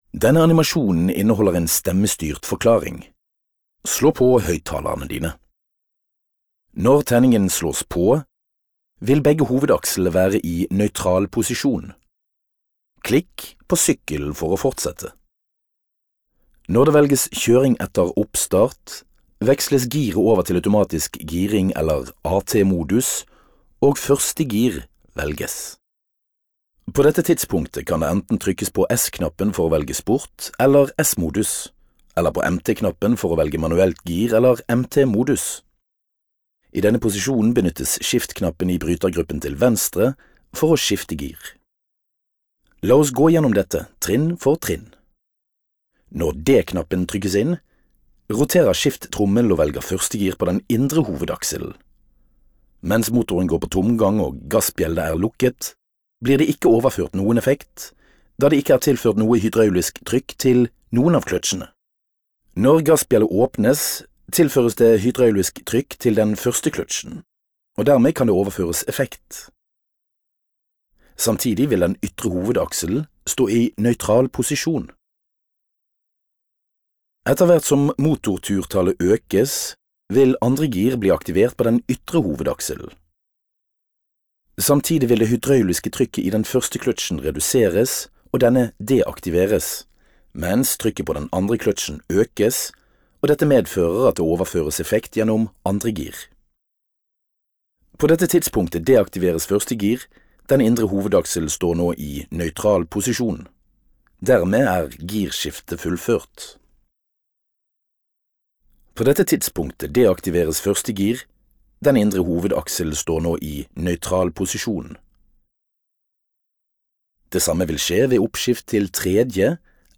Voiceover in Norway
Trustworthy, Informative, Versatile - Deep baryton
Sprechprobe: Industrie (Muttersprache):